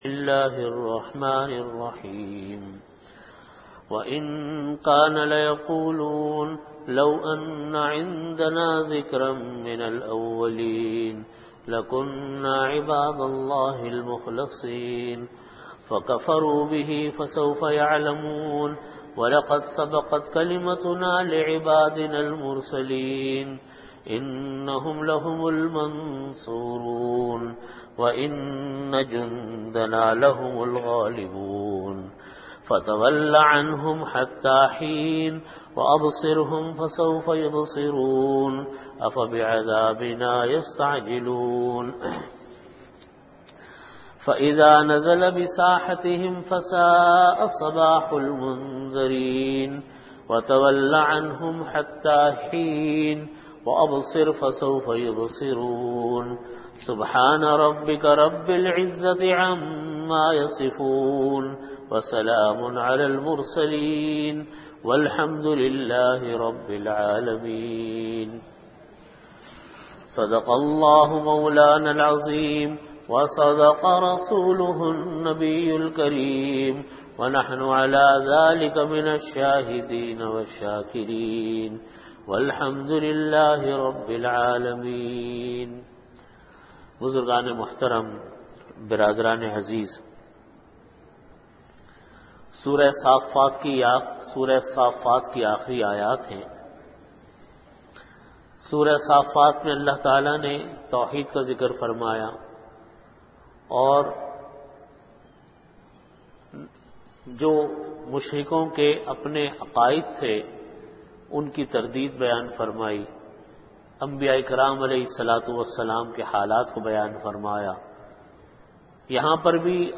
Tafseer Surah Saaffaat - XI
Venue: Jamia Masjid Bait-ul-Mukkaram, Karachi